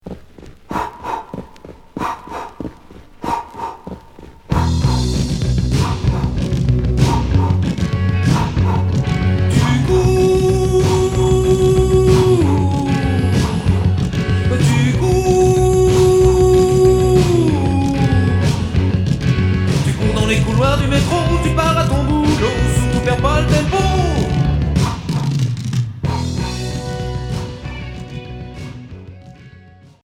Rock Premier 45t